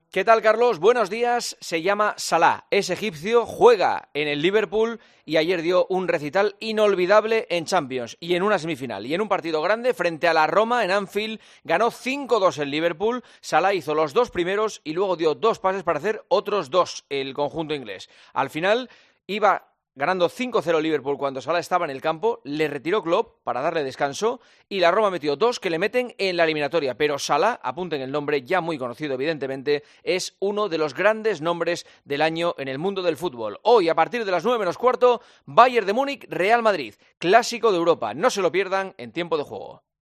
Escucha la opinión sobre la actualidad deportiva del director de 'El Partidazo' de COPE